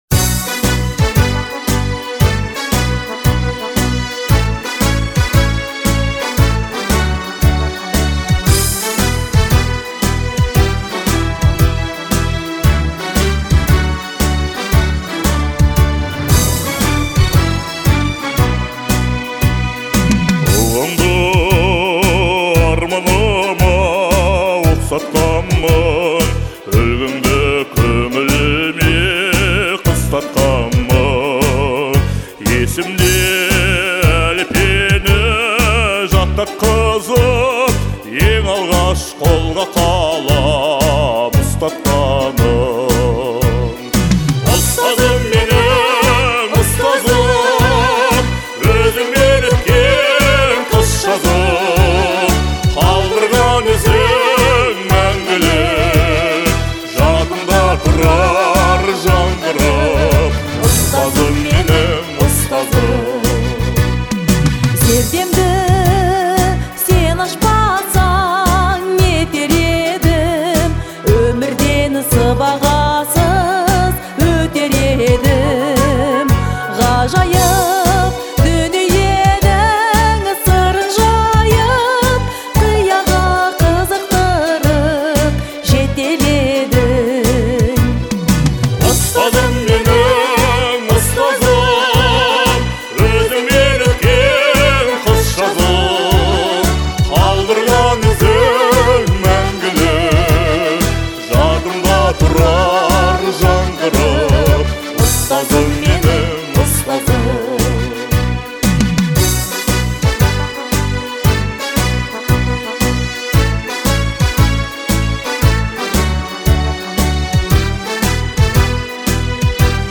относится к жанру казахской народной музыки